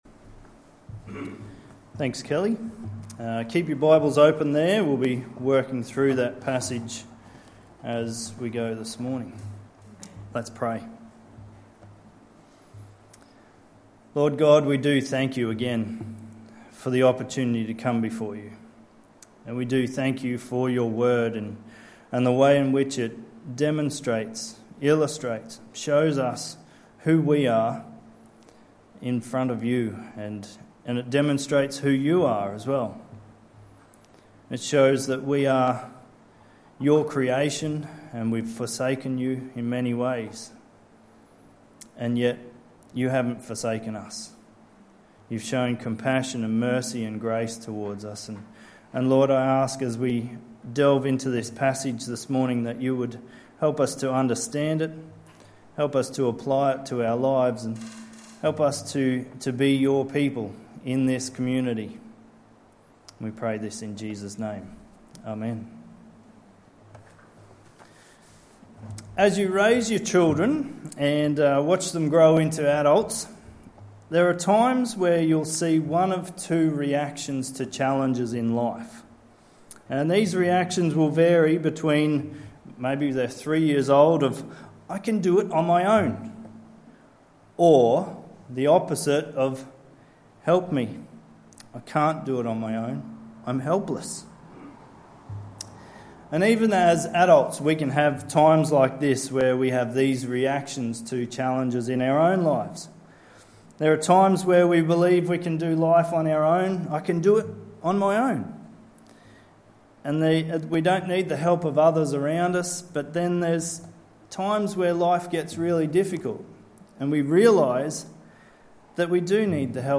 27/11/2022 Sunday Service